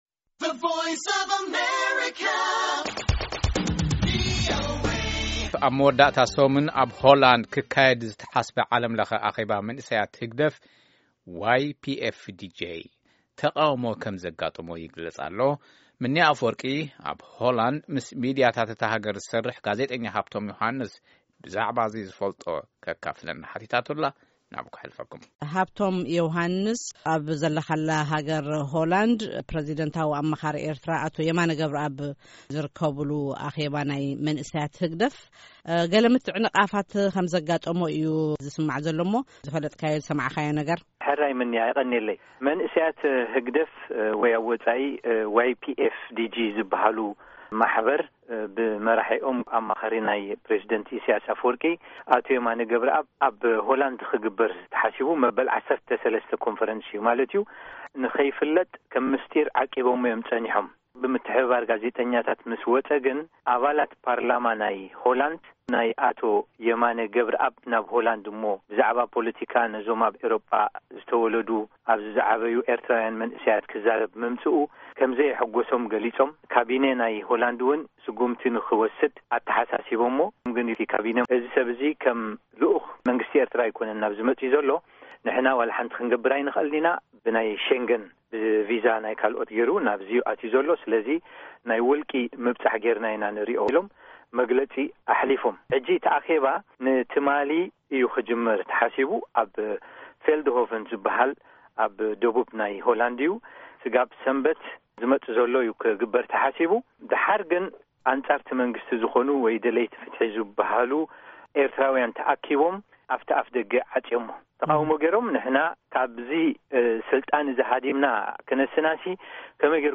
ቃለ መጠይቕ